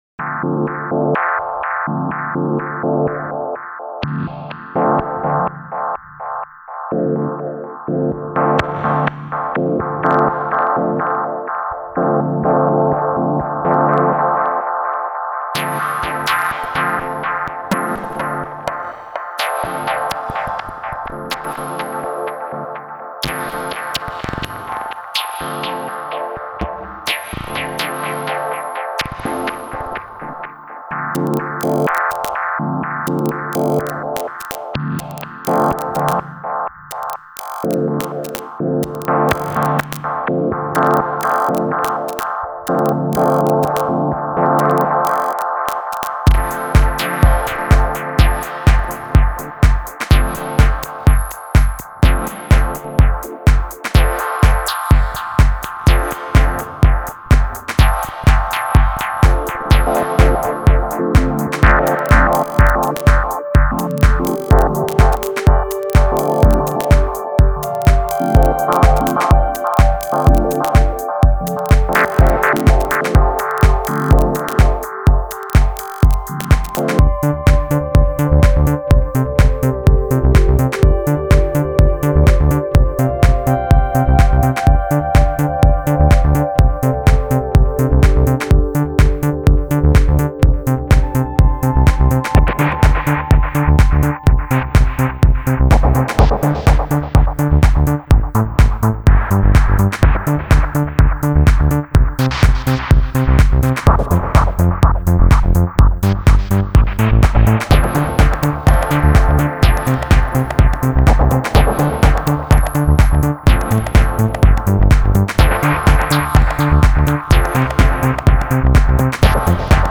Genre: IDM.